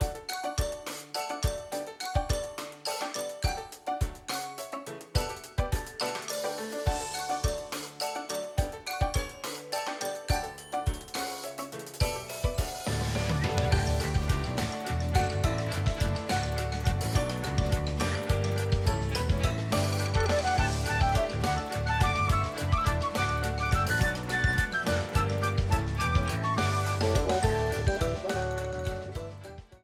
A red streamer theme
Ripped from the game
clipped to 30 seconds and applied fade-out